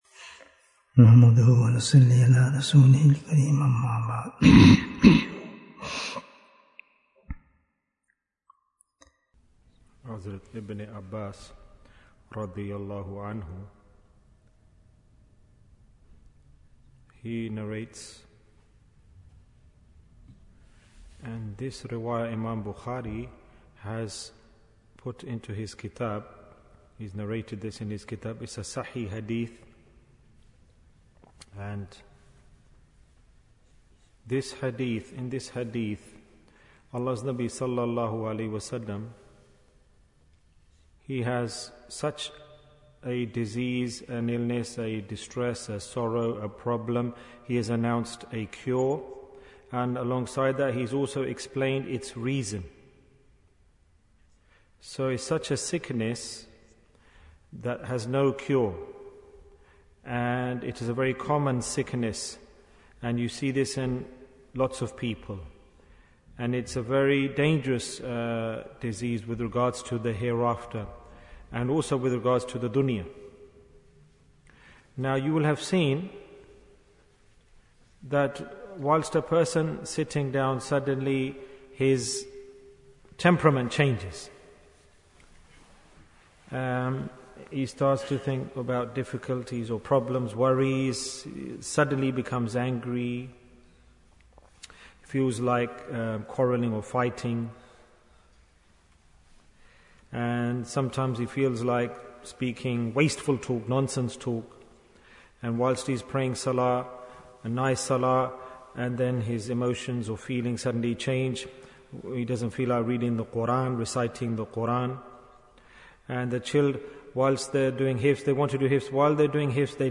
Cure for Thoughts Bayan, 22 minutes28th May, 2023